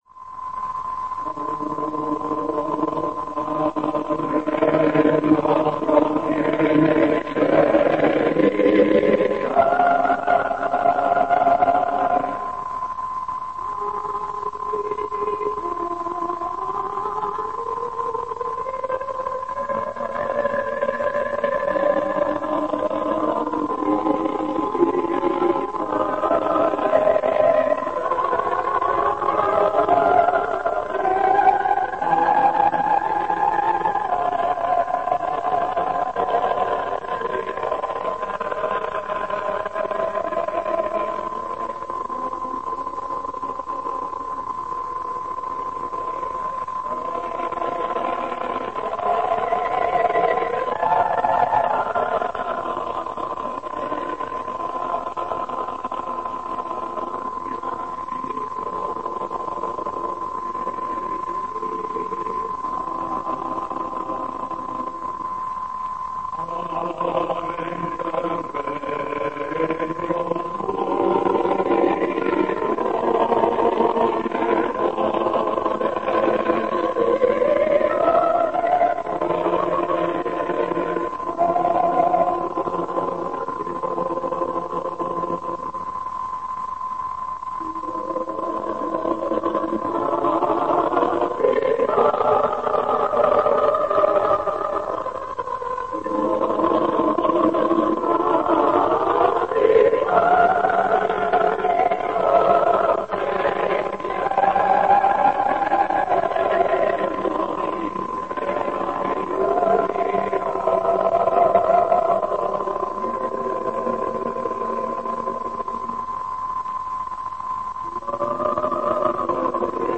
Zene
Zene 1956. november 04. 10:01 ● 07:49 ► Meghallgatom Műsor letöltése MP3 00:00 00:00 A műsor leirata zene Elhangzott Verdi - Pater Noster. A philadelphiai Coral együttes előadásában hallották.